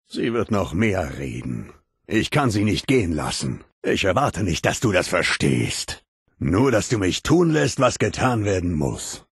Der innere Ghul: Audiodialoge